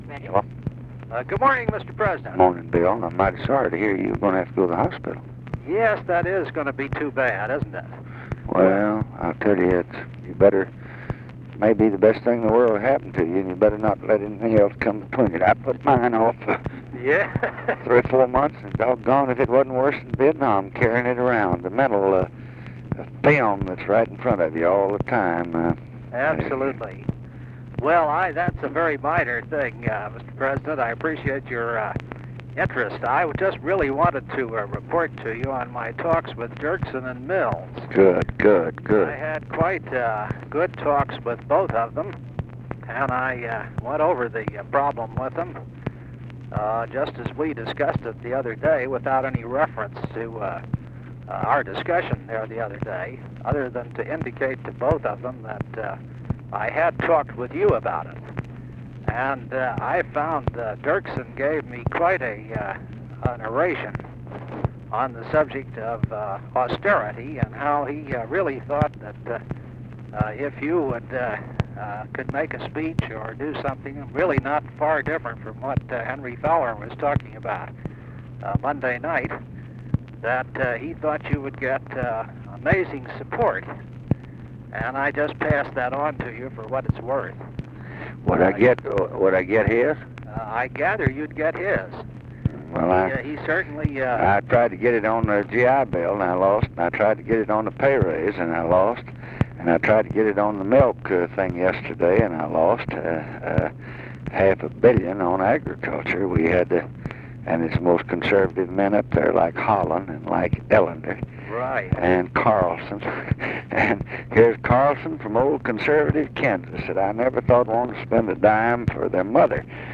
Conversation with WILLIAM MCC. MARTIN, June 30, 1966
Secret White House Tapes | Lyndon B. Johnson Presidency Conversation with WILLIAM MCC.